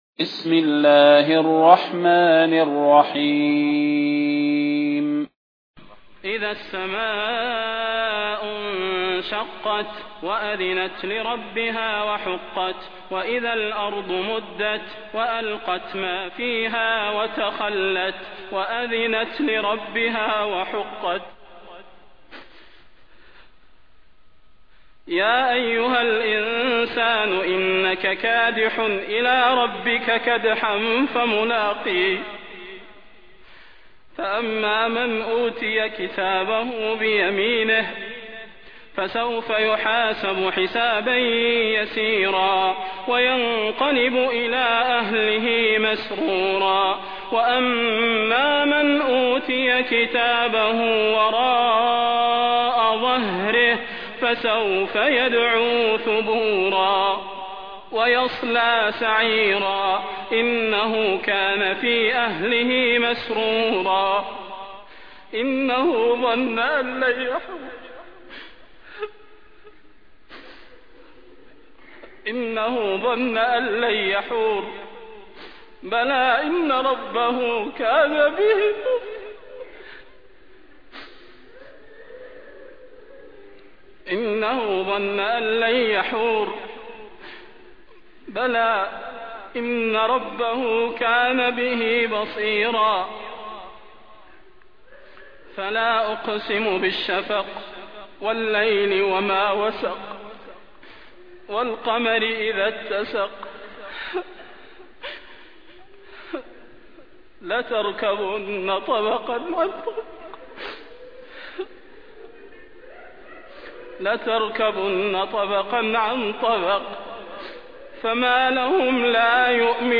المكان: المسجد النبوي الشيخ: فضيلة الشيخ د. صلاح بن محمد البدير فضيلة الشيخ د. صلاح بن محمد البدير الانشقاق